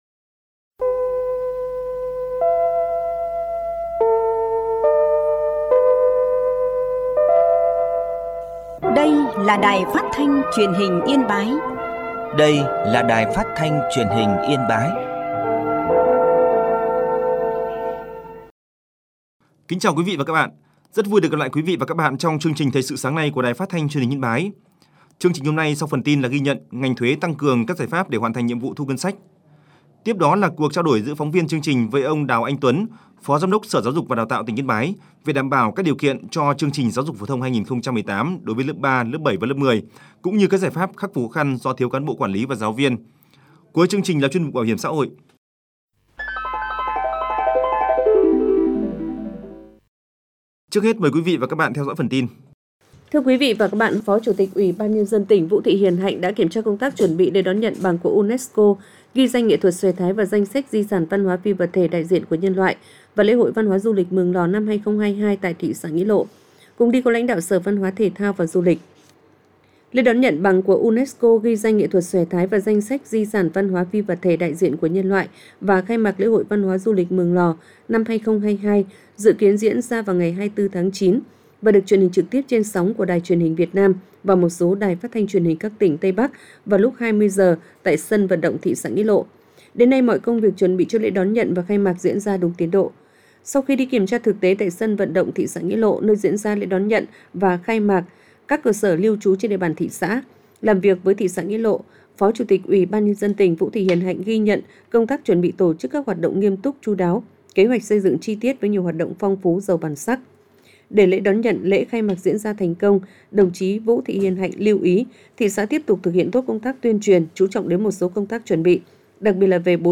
Thoi_su_sang_06.mp3